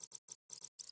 beeps3.ogg